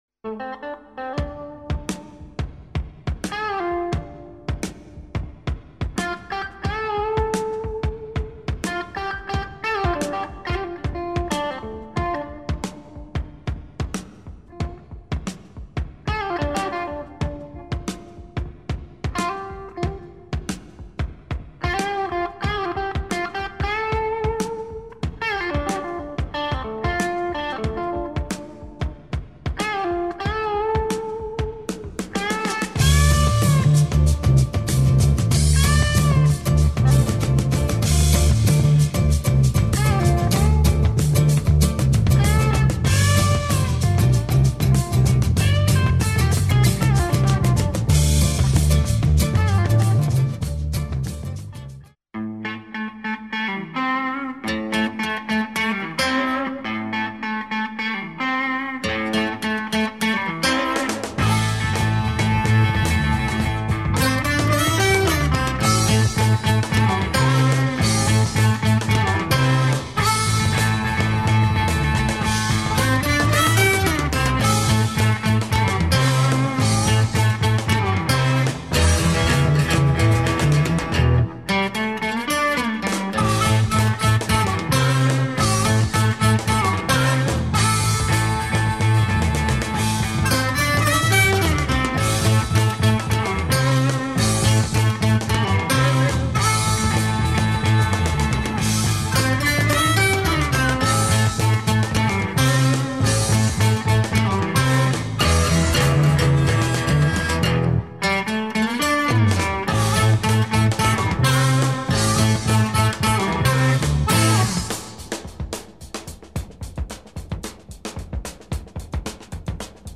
Excellent blues-prog rock project from Poland !
Psych & Prog